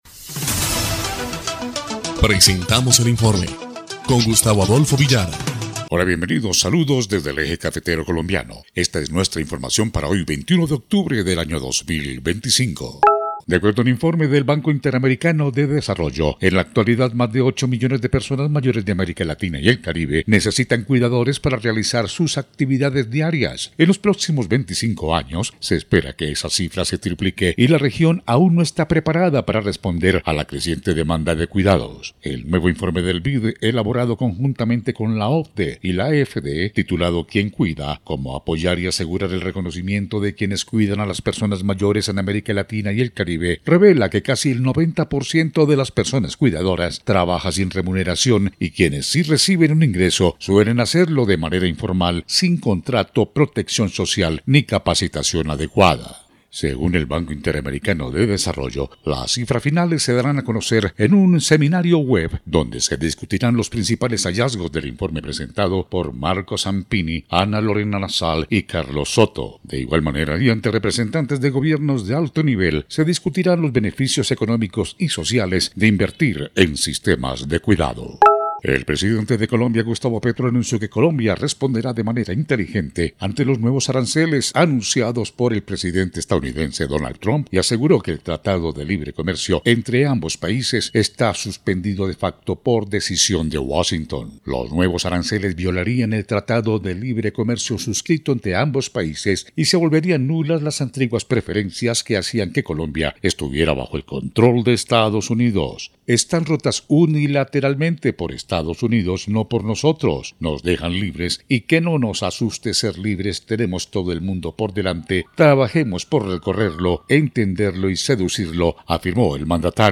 EL INFORME 1° Clip de Noticias del 21 de octubre de 2025